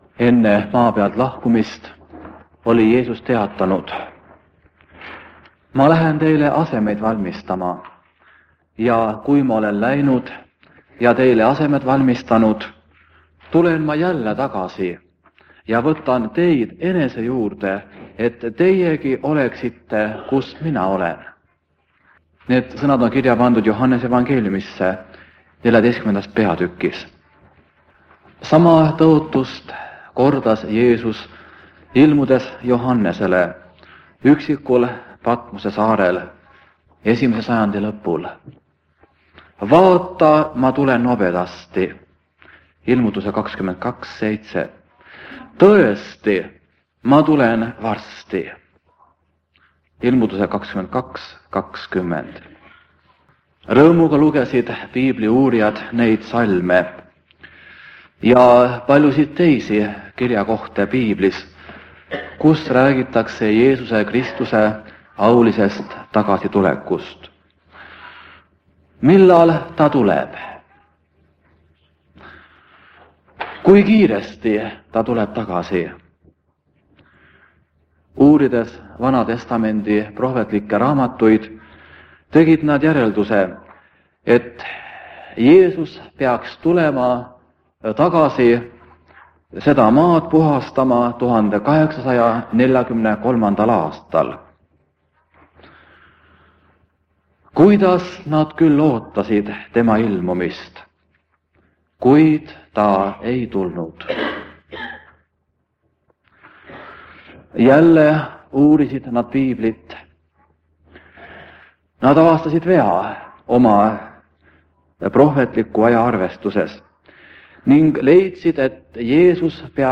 2 x instrumentaalmuusikat
Koosolek vanalt lintmaki lindilt.